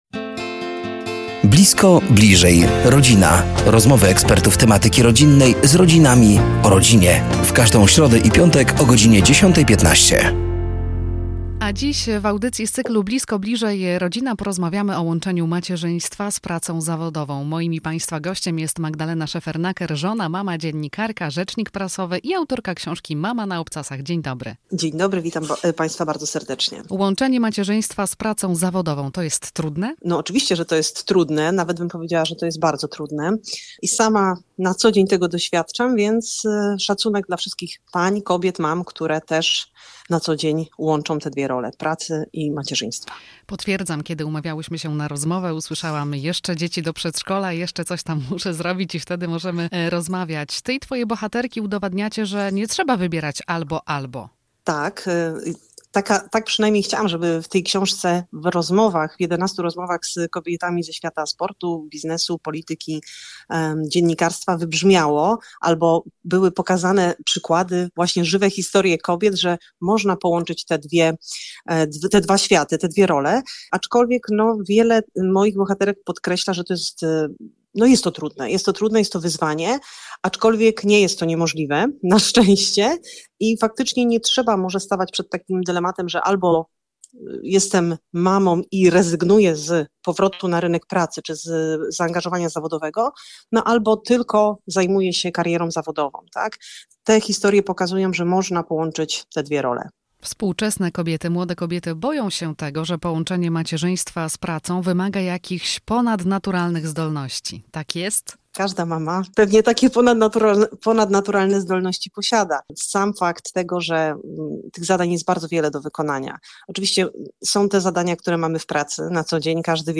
Blisko. Bliżej. Rodzina! To cykl audycji na antenie Radia Nadzieja. Do studia zaproszeni są eksperci w temacie rodziny i rodzicielstwa.